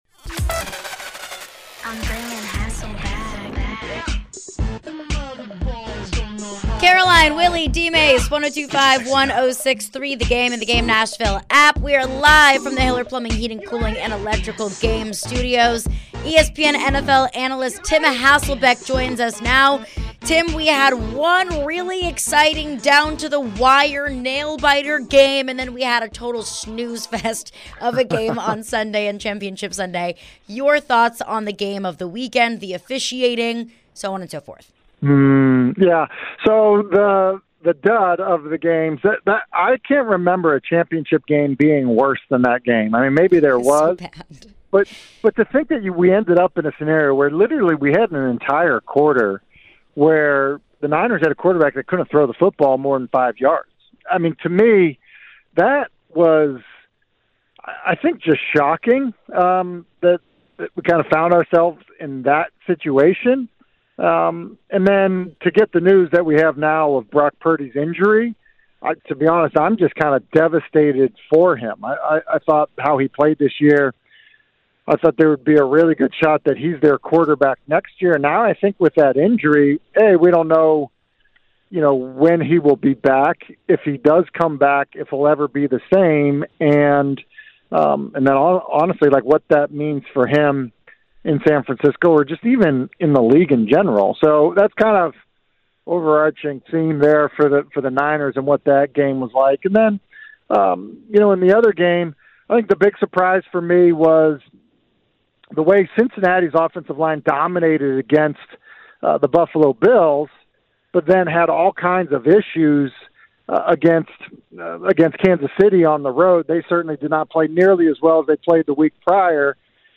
Tim Hasselbeck Interview (1-31-23)